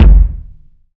KICK.127.NEPT.wav